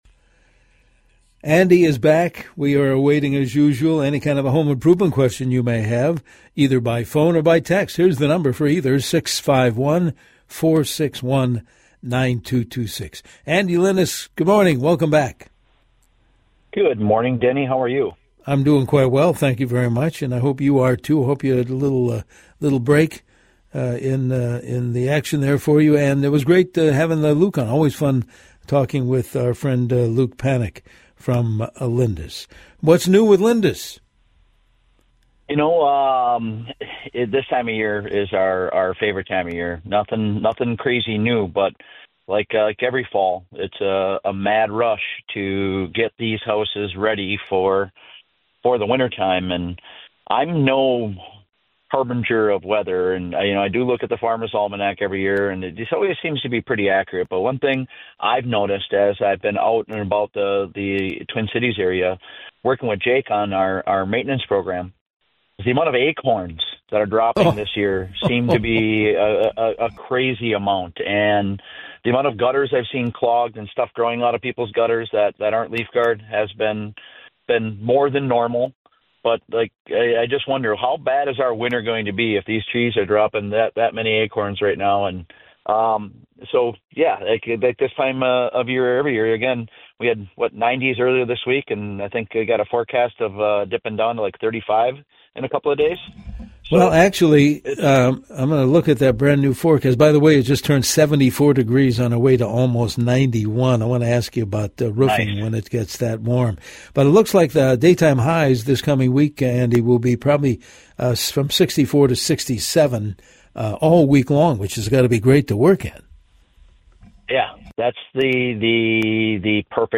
Discussion Highlights: The surge of AI and data center growth driving electrical and low voltage packages. Understanding Division 27 …